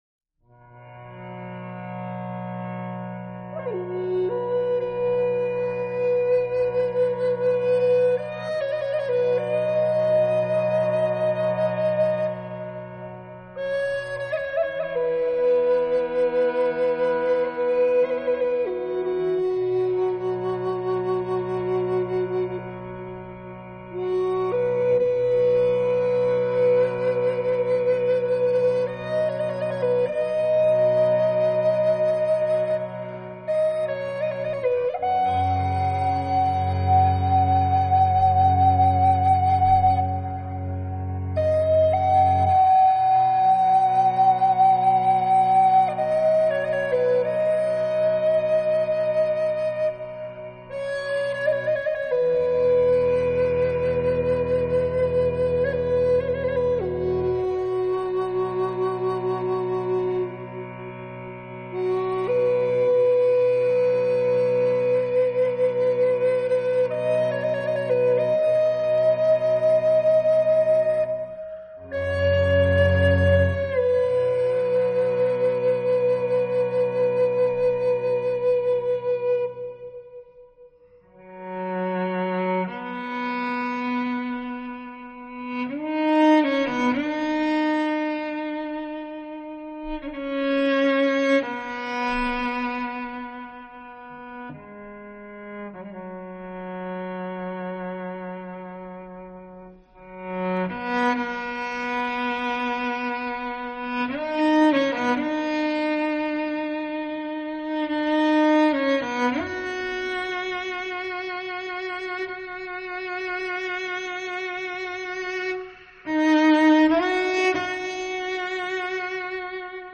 典雅的大提琴和悠扬的印第安长笛，奇特而美妙的音乐之旅。